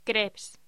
Locución: Crèpes